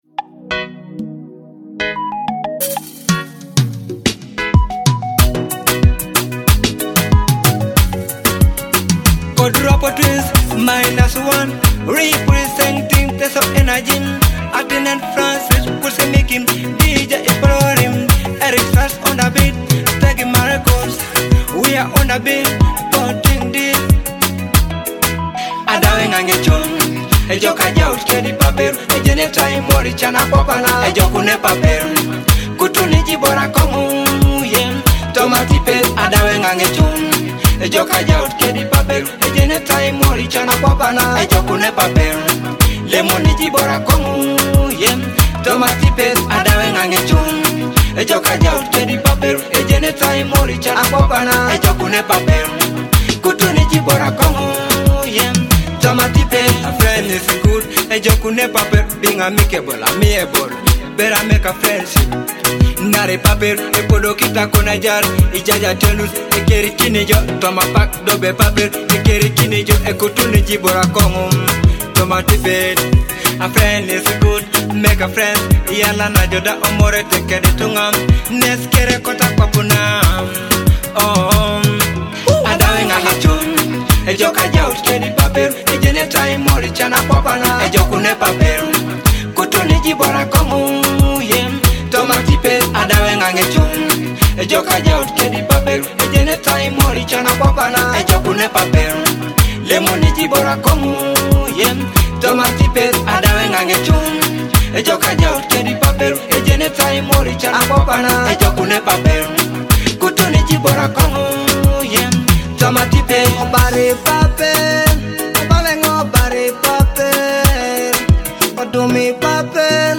a powerful Afrobeat song about the value of friendship.